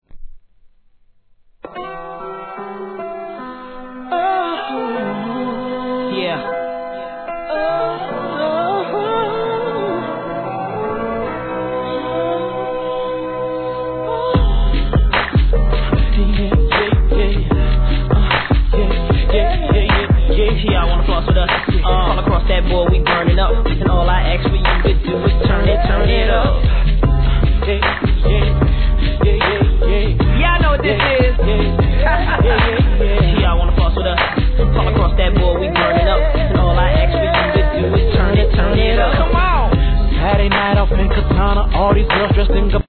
HIP HOP/R&B
クラップ音の効いたミディアムテンポで、メロディアスなネタ使い♪。